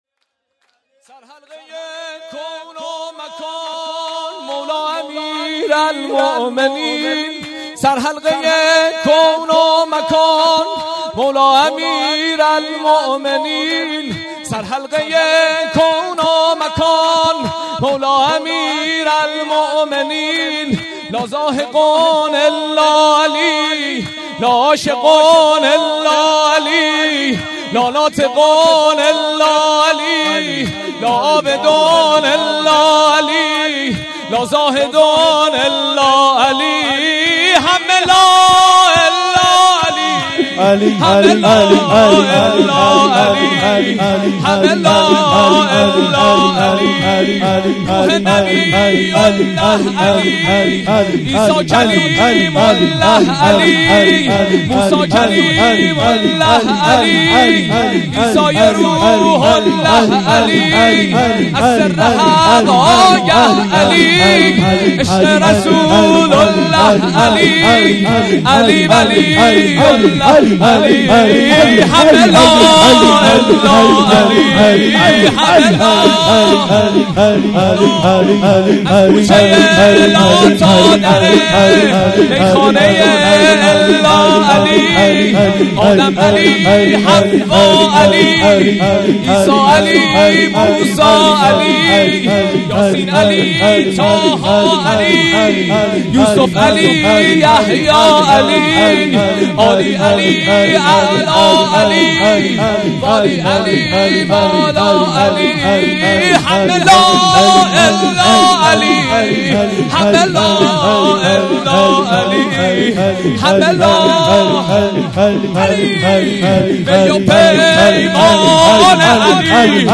جشن میلاد حضرت رسول (ص) و امام جعفر صادق (ع) 1395
سرود «1»